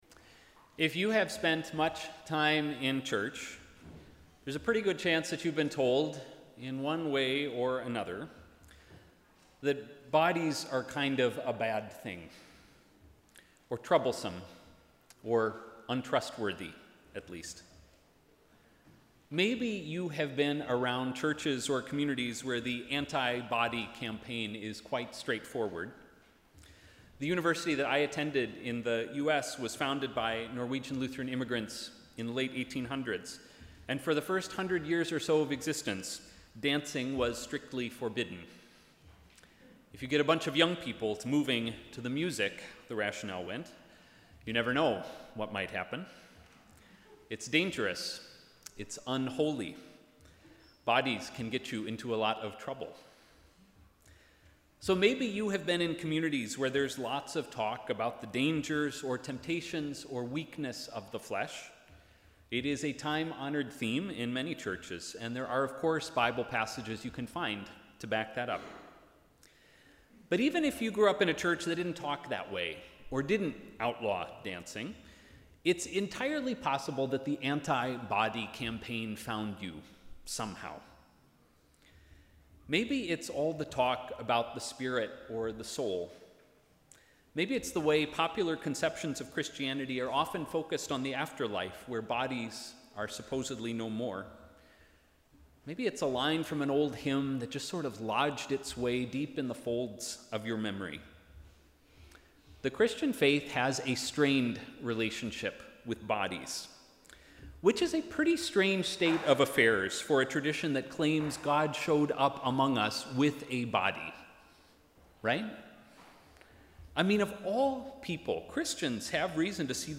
Sermon: ‘Holy and acceptable’